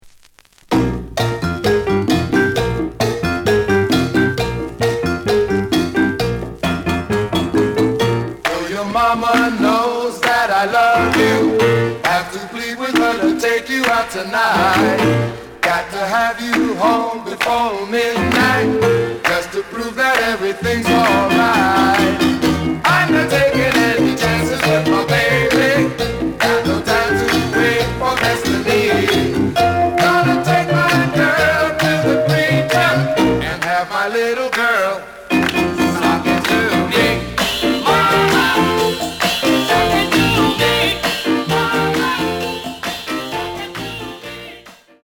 The audio sample is recorded from the actual item.
●Genre: Latin